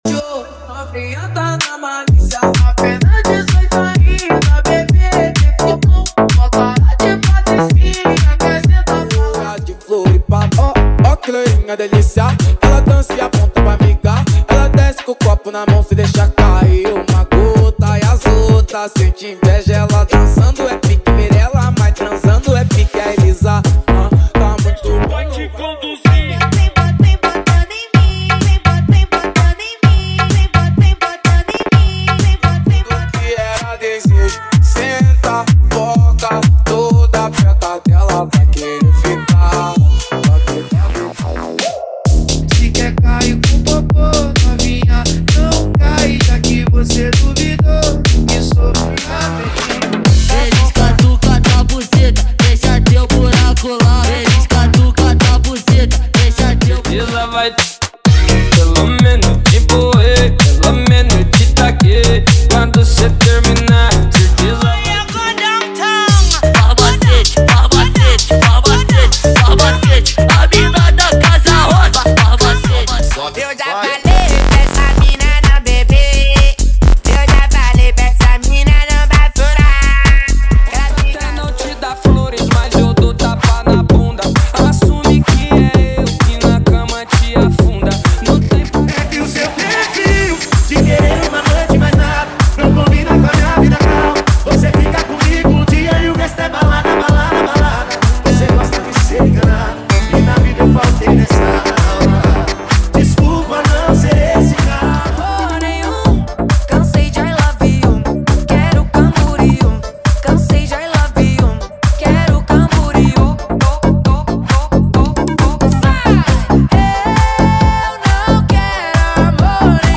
🔥 Mega Minimal
🔥 Mega Funk Sertanejo
🔥 Mega Automotivo
✔ Músicas sem vinhetas